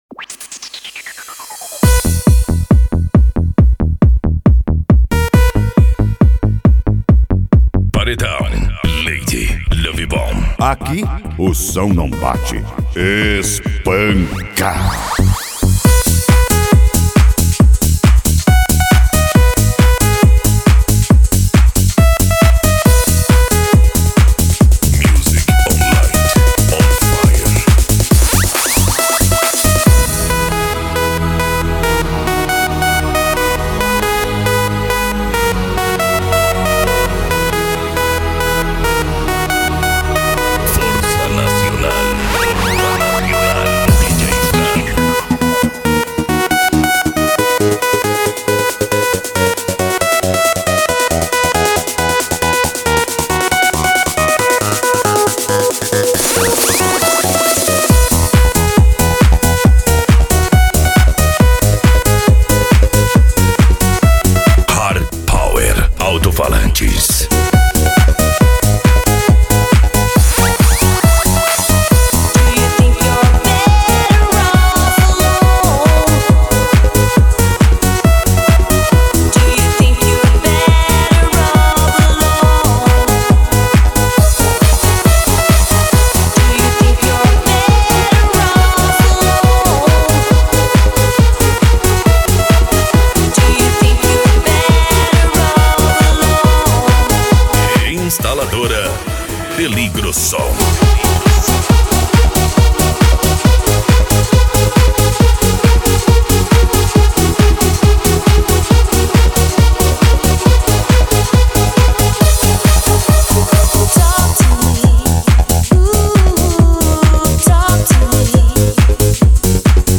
Deep House
Electro House
Eletronica